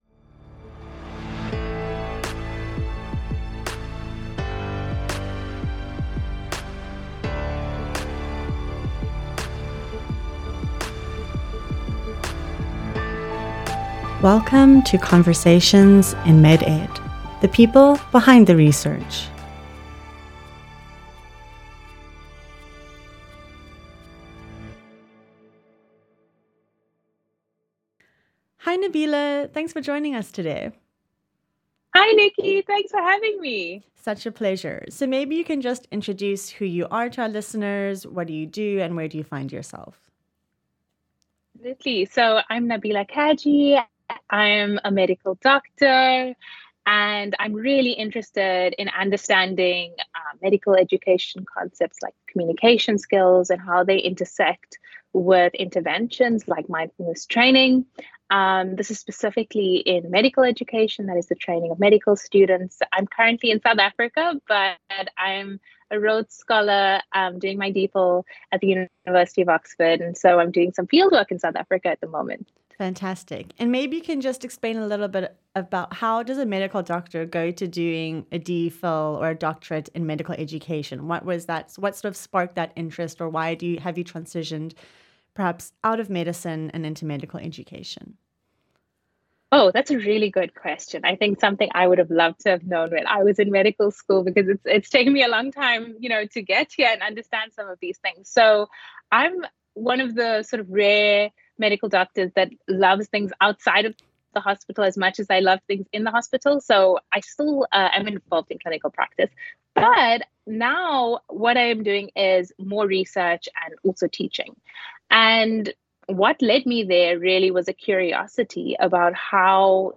Chatting